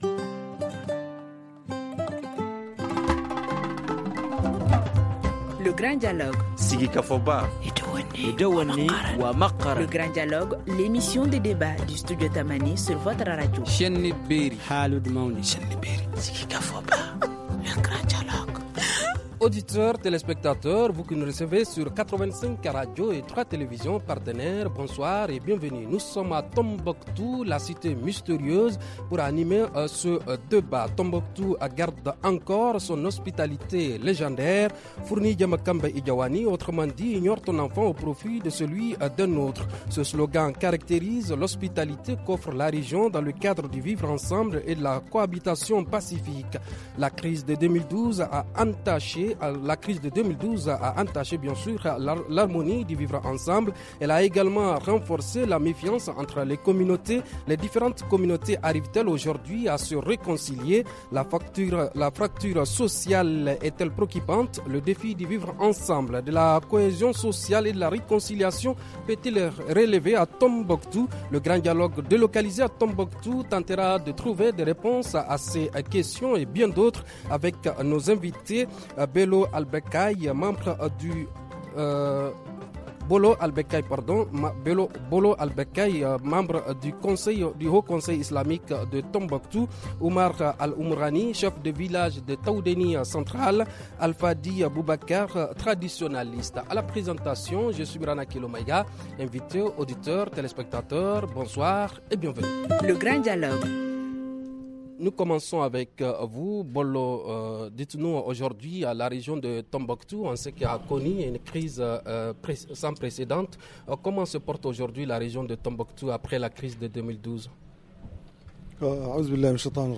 Le Grand Dialogue délocalisé à Tombouctou tentera de trouver des réponses à ces questions et bien d’autres avec nos invités :